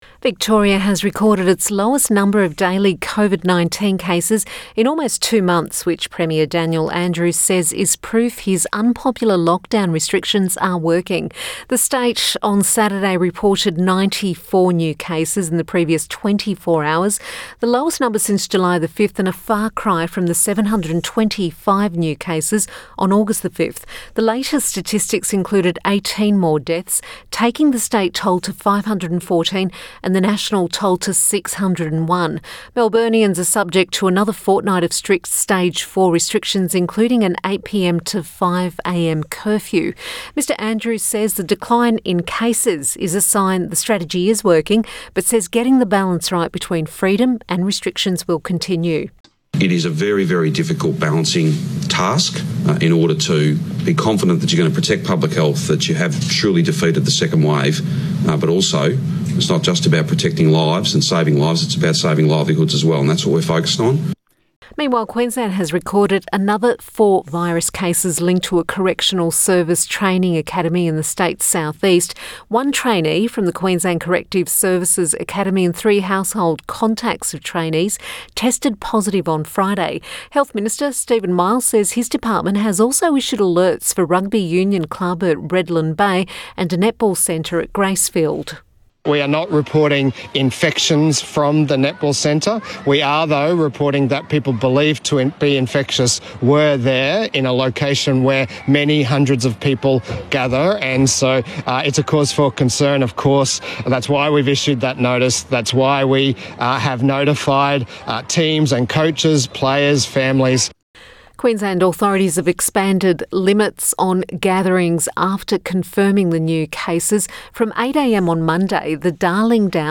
Victorian Premier Daniel Andrews speaks to reporters on Saturday, 29 August.